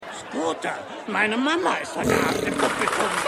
Synchronstudio: Iyuno Germany GmbH